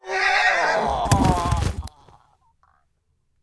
horseman_die3.wav